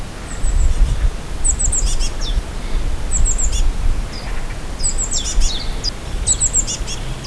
Abb. 03:  Meise, im Hintergrund ist ein Zilpzalp (Abb. 05) zu hören.
meise_b-mono22.wav